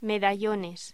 Locución: Medallones
voz